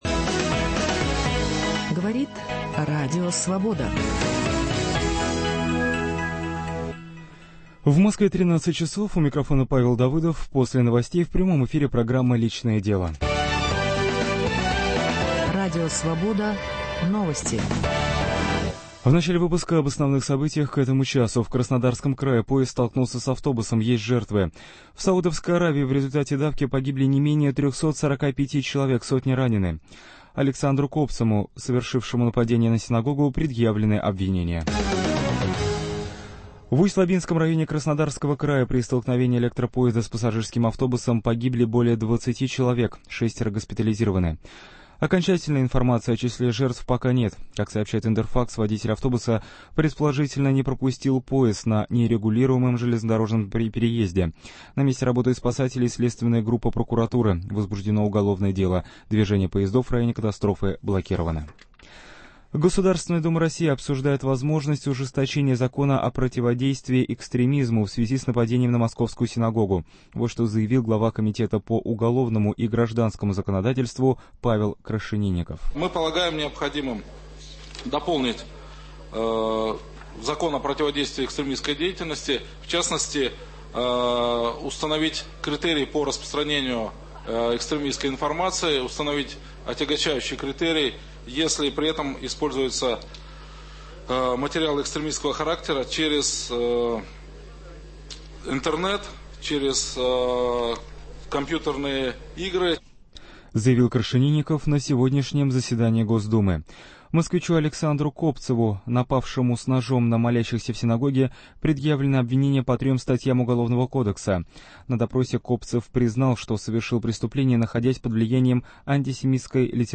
Доверительный разговор в прямом эфире о личном - страхах, надеждах, сомнениях, чувствах, видении мира и семейных проблемах. На звонки в студию отвечают эксперты: психологи, социологи, философы, священники, писатели.